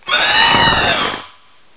The cries from Chespin to Calyrex are now inserted as compressed cries
corviknight.aif